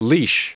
Prononciation du mot leash en anglais (fichier audio)
Prononciation du mot : leash